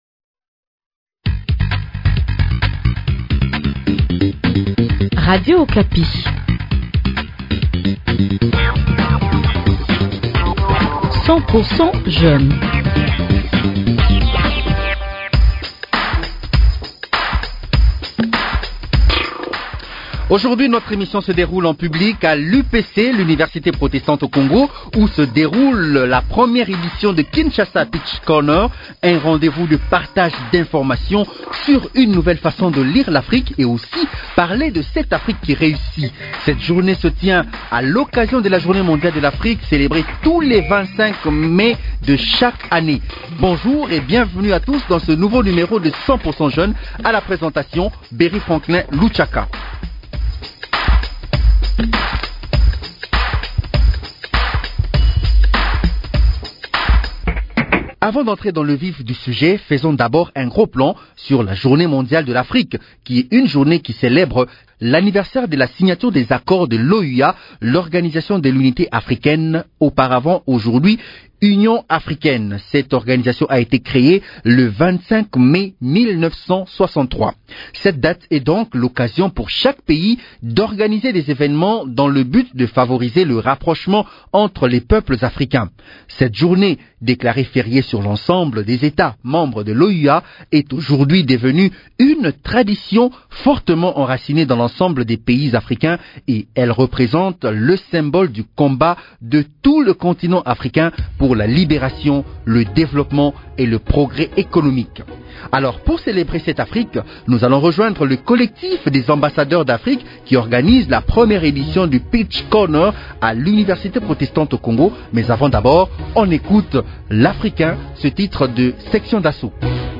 A l’occasion de la journée mondiale de l’Afrique célébrée tous les 25 mai de chaque année, l’équipe de 100% jeunes s’est rendue à l’Univeristé Protestante du Congo, UPC pour la première édition du Kinshasa Pitch Corner. Un rendez-vous de partage d’informations sur une nouvelle façon de lire l’Afrique et aussi de parler de cette Afrique qui réussit.